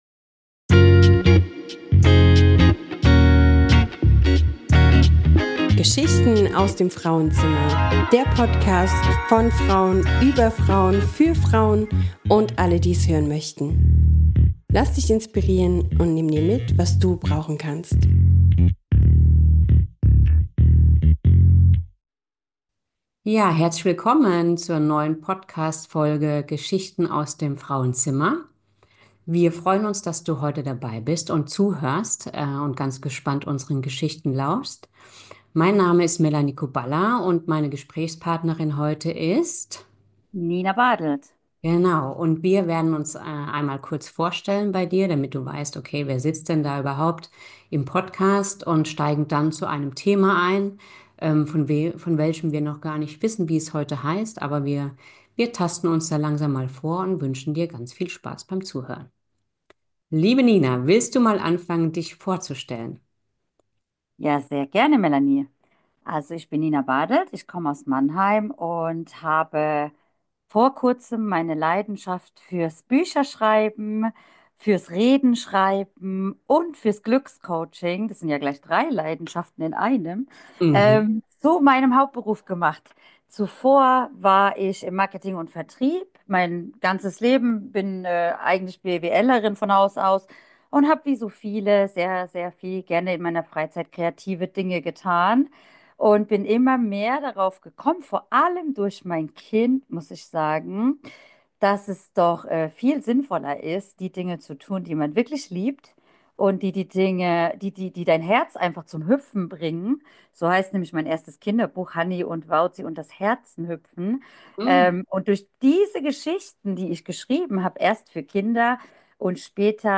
In dieser Folge begegnen sich zwei inspirierende Frauen